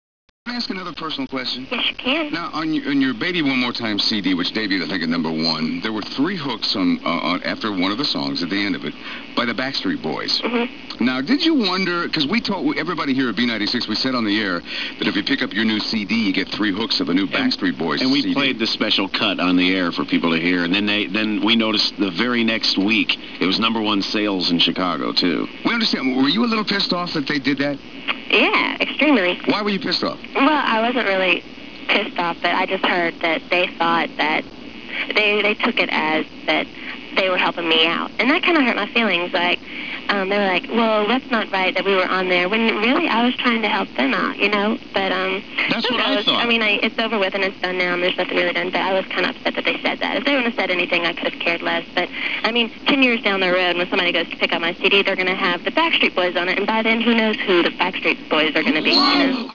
If u dont belive me here is the wave where she is saying that stupid thing